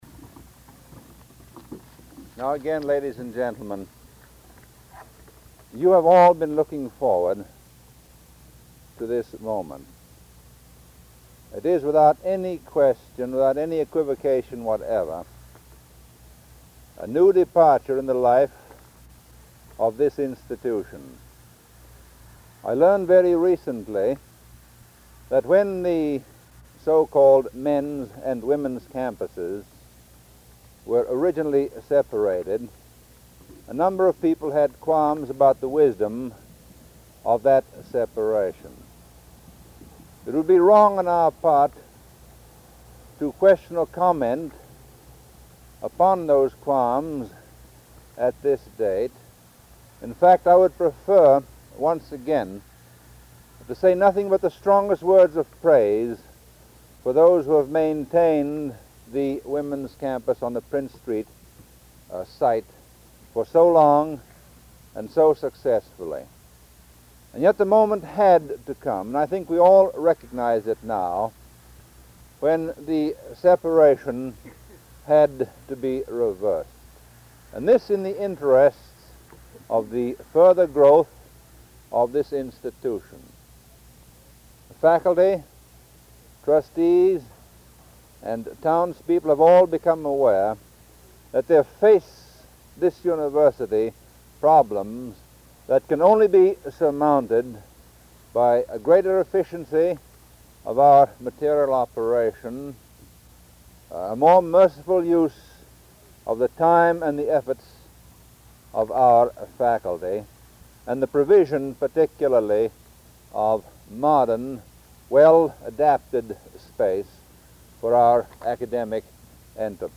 1952 Groundbreaking for Susan B. Anthony Hall and Spurrier Gymnasium
Remarks